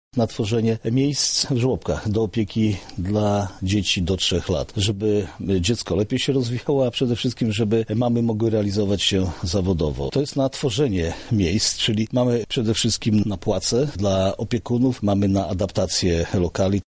Przekazujemy na ten cel pieniądze pochodzące z unijnych funduszy – mówi Marszałek Województwa Jarosław Stawiarski.